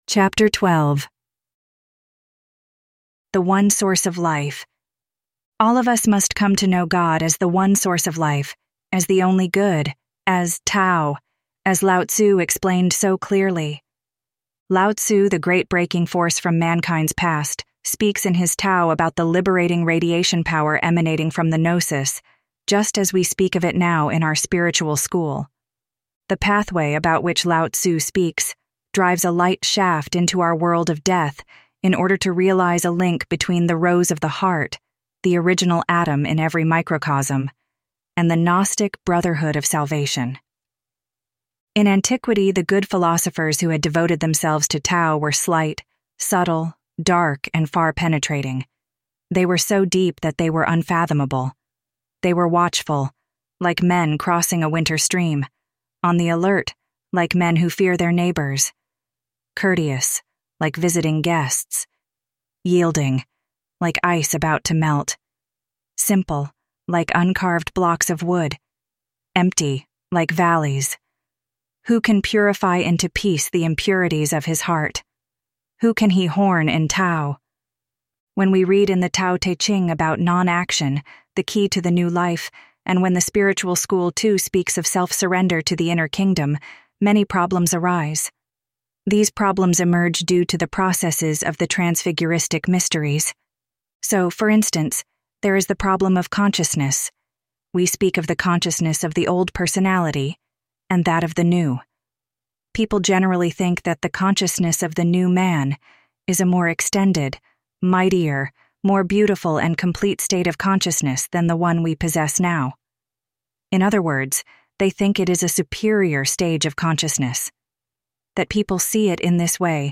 Audio Books of the Golden Rosycross